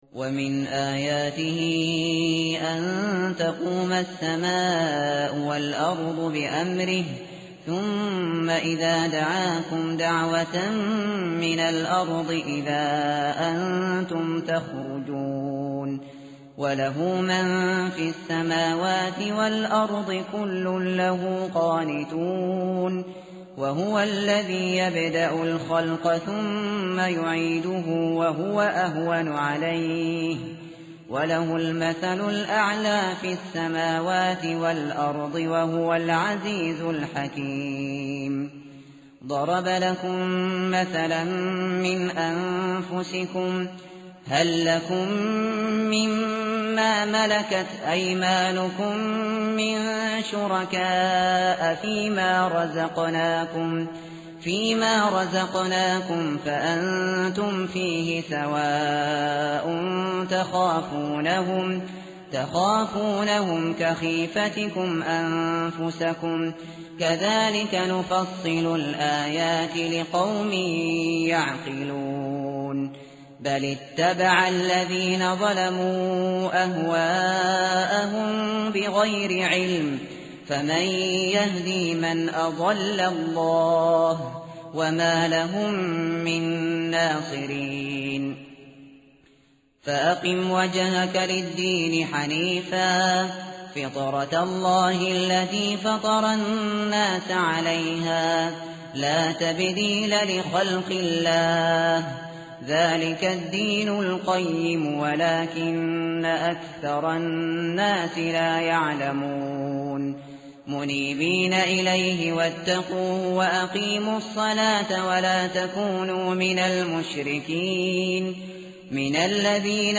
tartil_shateri_page_407.mp3